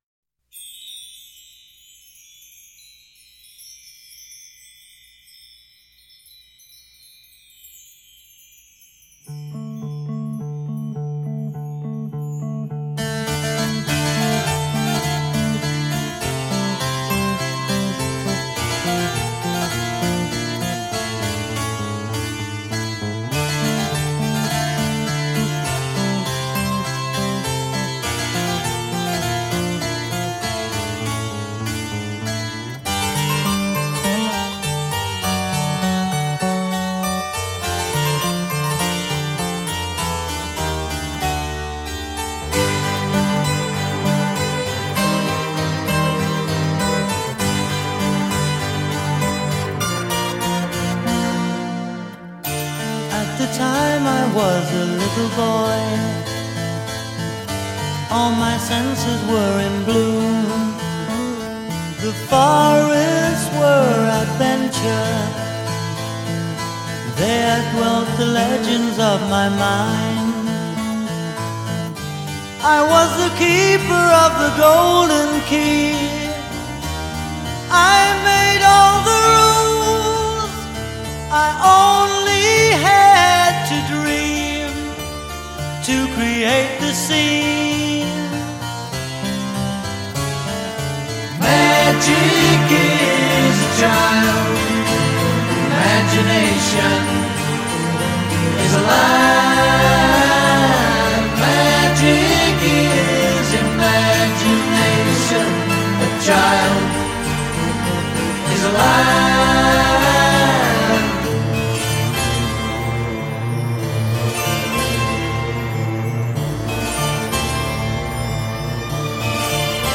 and least prog-sounding
Most of it sounds like straight-ahead 70’s British rock